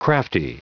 Prononciation du mot crafty en anglais (fichier audio)
Prononciation du mot : crafty